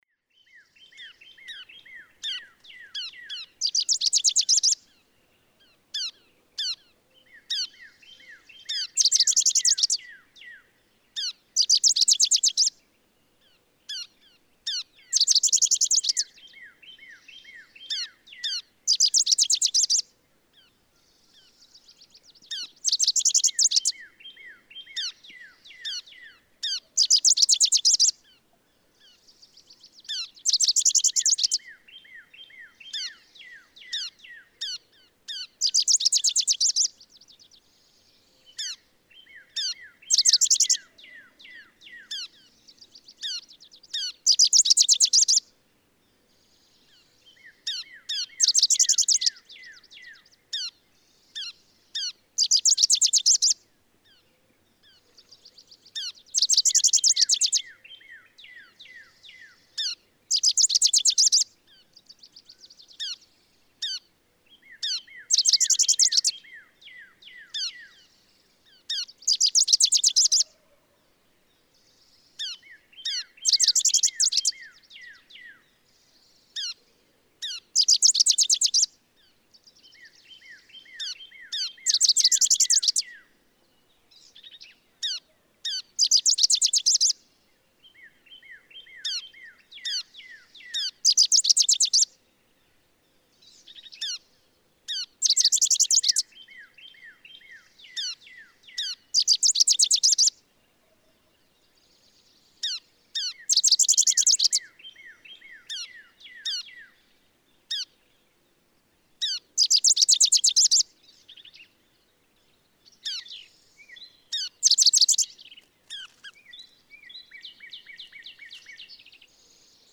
Rufous-crowned sparrow
♫703. Here's the best segment from the entire 55-minute dawn recording (♫704, below), almost five minutes of superb, close singing by this sparrow. I love his repeated notes between the songs, four or five descending dear notes; at one point he calls 17 times in six seconds (beginning at 2:11). Like the dawn singing of so many other songbirds, songs are not enough, and an extra enthusiasm is conveyed by filling time between songs with calls.
I check out the first eight songs and find two different ones, in the pattern A B A B B A B A. I skip to the last eight songs and find two others, in the pattern M N N M N M N N (letters chosen somewhat at random).
Chaparral Wildlife Management Area, Artesia Wells, Texas.
703_Rufous-crowned_Sparrow.mp3